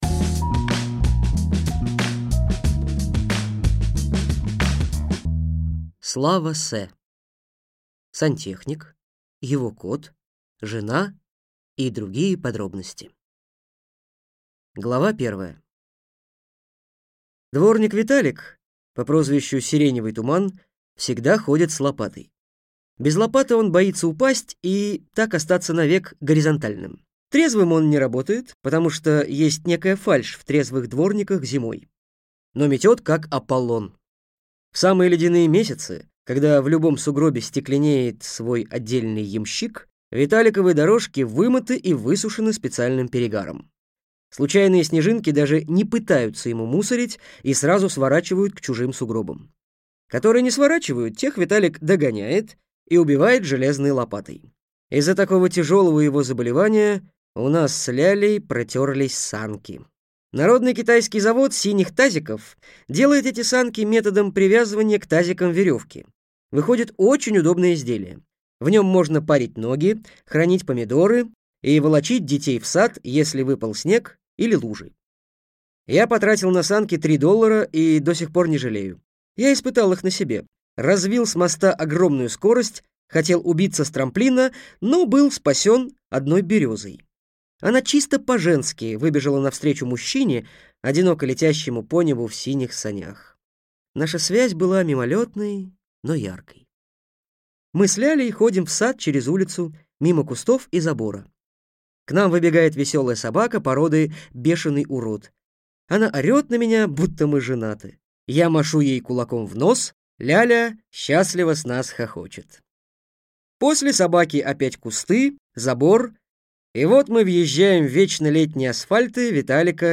Аудиокнига Сантехник, его кот, жена и другие подробности | Библиотека аудиокниг